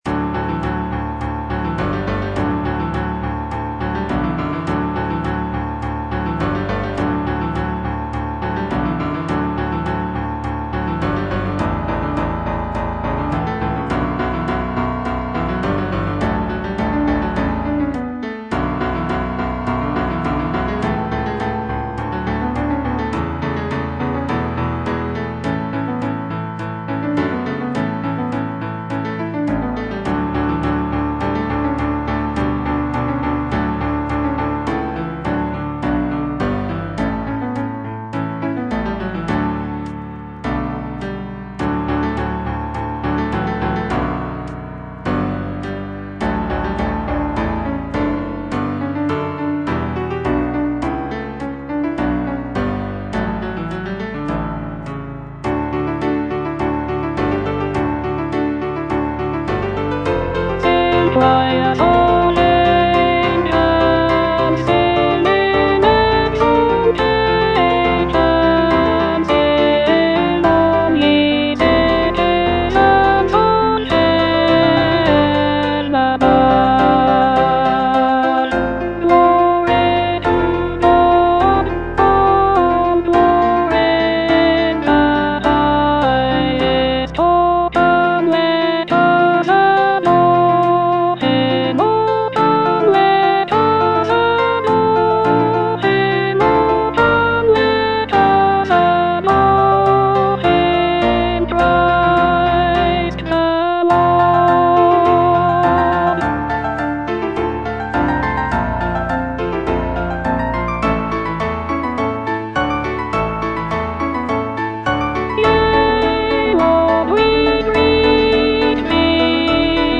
Alto II (Voice with metronome)
Christmas carol
incorporating lush harmonies and intricate vocal lines.